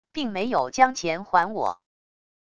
并没有将钱还我wav音频生成系统WAV Audio Player